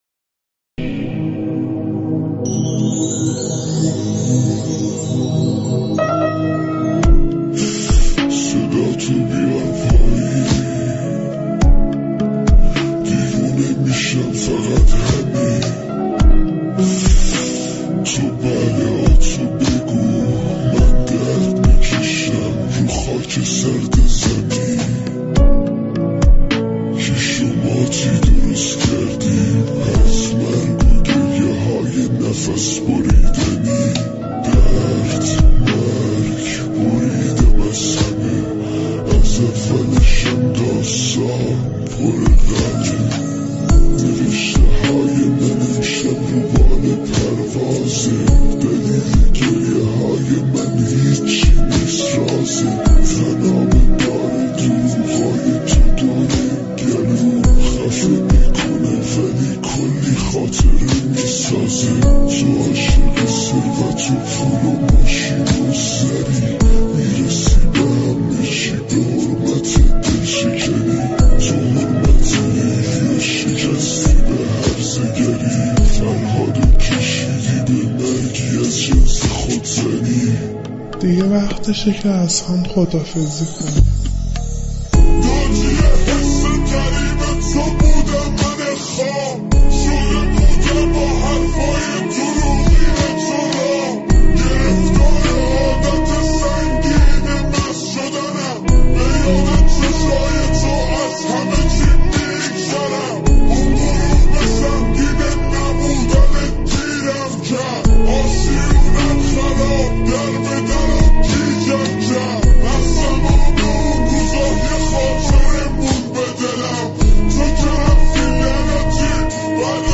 Arabic Music